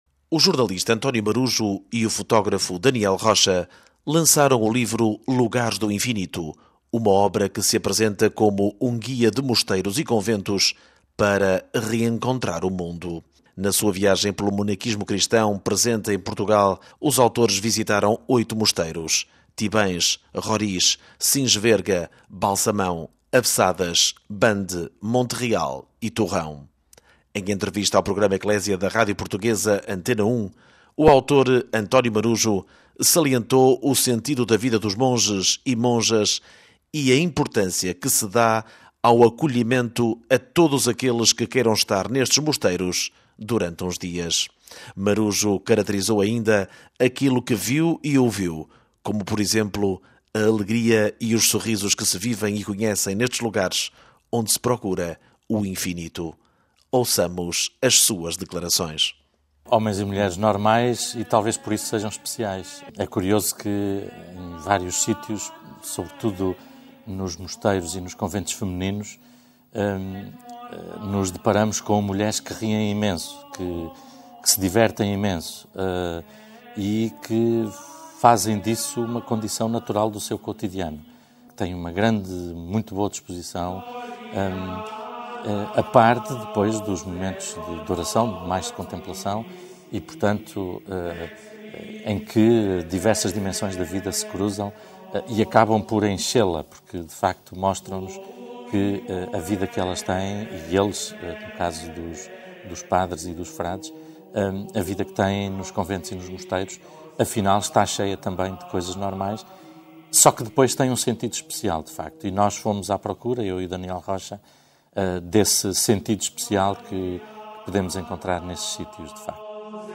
Em entrevista ao Programa Ecclesia da rádio portuguesa Antena 1